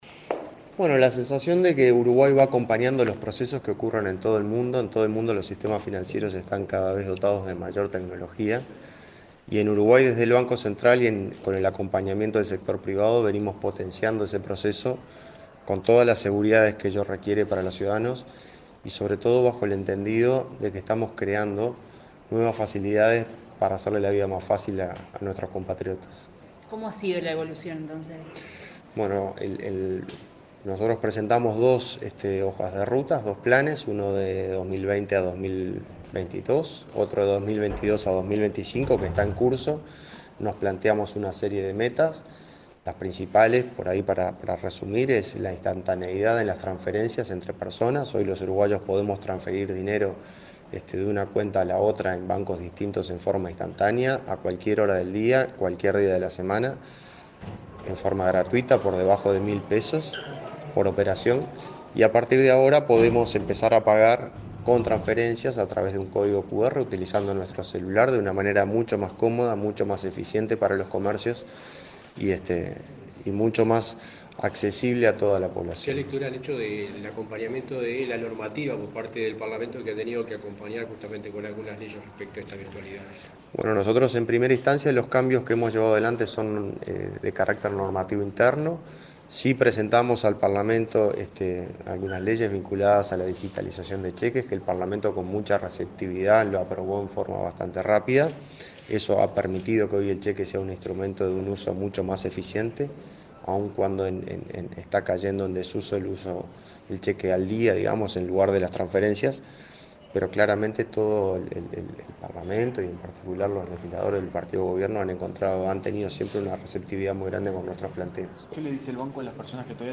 Declaraciones del presidente del BCU, Washington Ribeiro
El presidente del Banco Central del Uruguay (BCU), Washington Ribeiro, dialogó con la prensa, luego de presentar una rendición de cuentas de la hoja